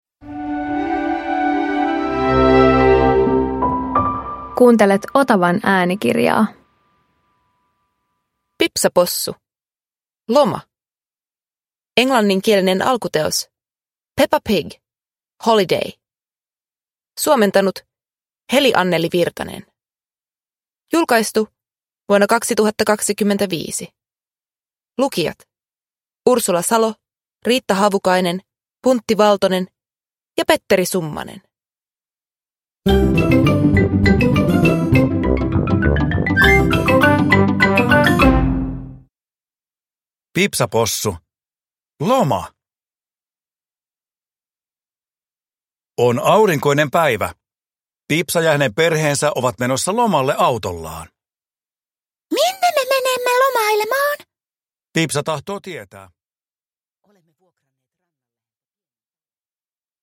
Pipsa Possu - Loma – Ljudbok